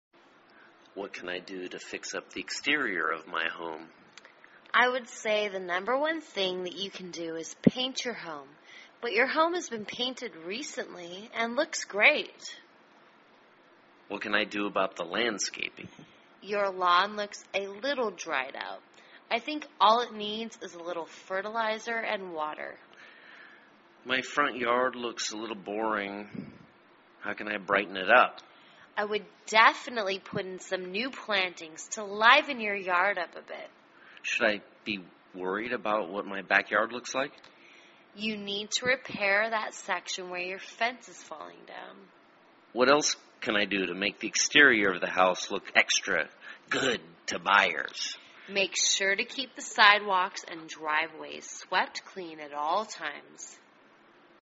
卖房英语对话-Fixing Up the Exterior(2) 听力文件下载—在线英语听力室